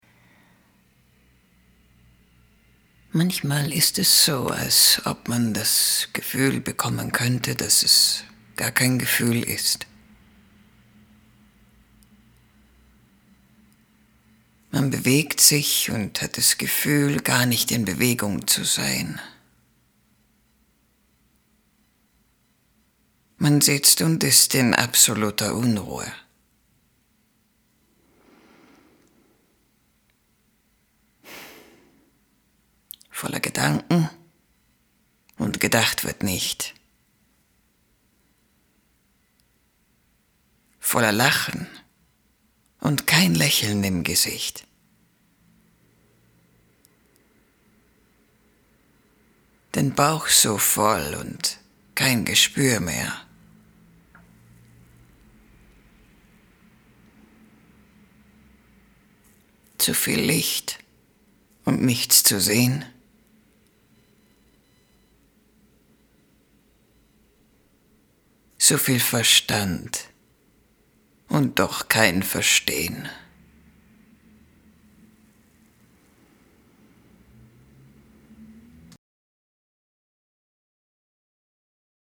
sehr variabel, dunkel, sonor, souverän, plakativ, markant
Mittel minus (25-45)
Erzählung - tief - eindringlich
Audio Drama (Hörspiel), Audiobook (Hörbuch), Doku, Game, Tale (Erzählung)